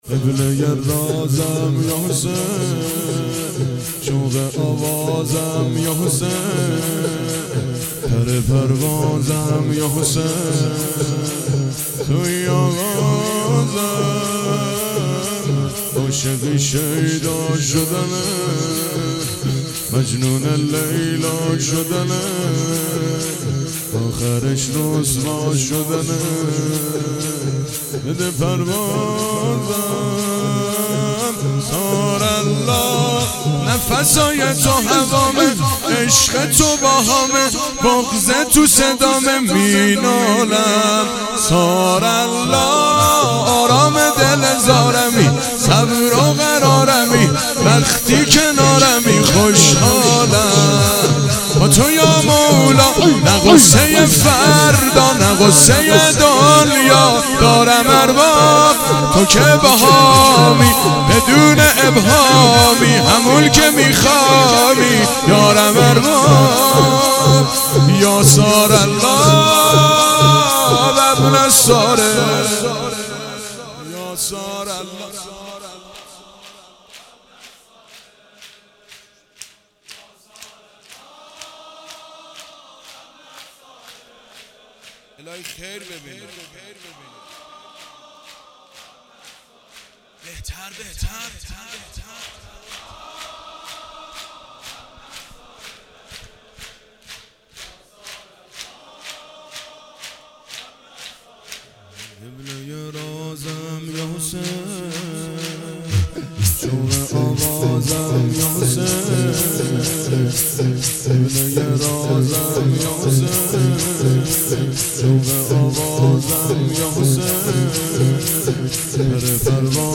نوحه شور